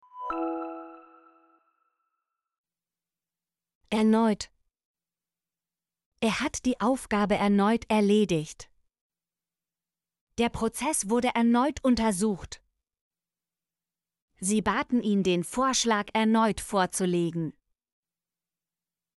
erneut - Example Sentences & Pronunciation, German Frequency List